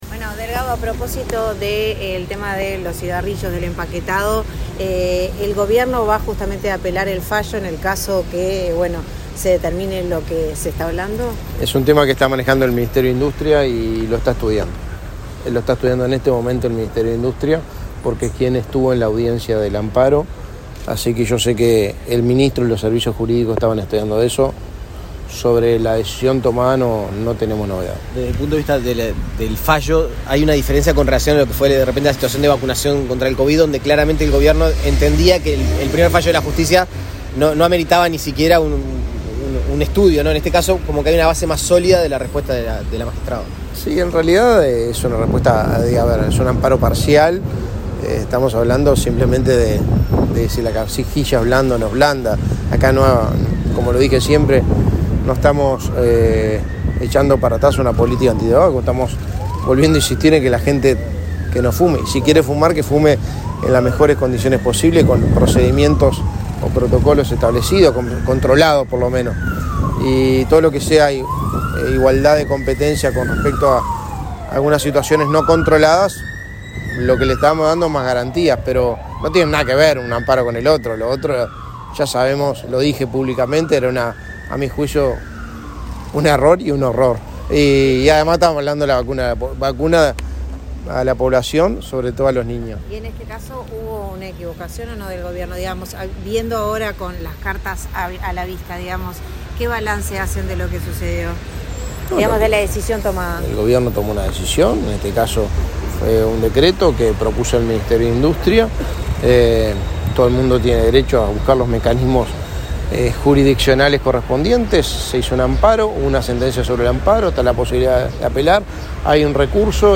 Declaraciones del secretario de Presidencia, Álvaro Delgado
Luego dialogó con la prensa.